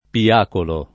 [ pi- # kolo ]